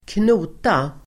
Uttal: [²kn'o:ta]